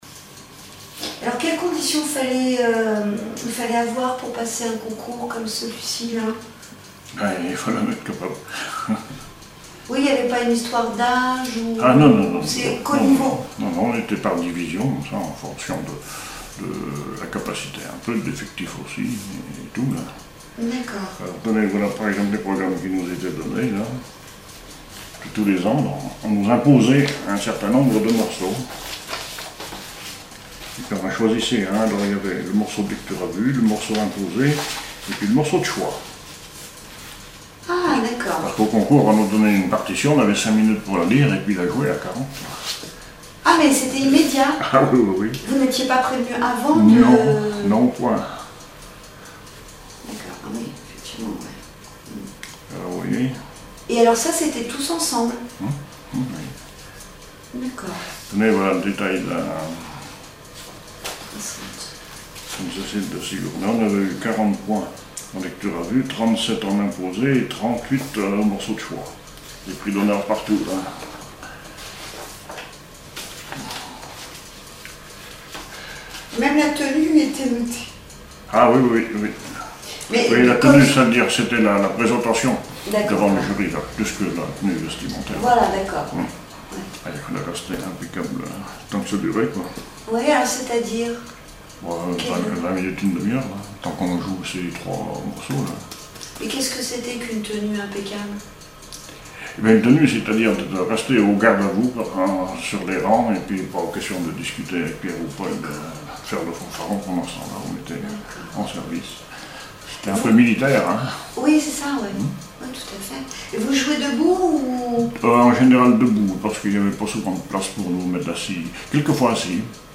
témoignages sur la musique et une chanson
Catégorie Témoignage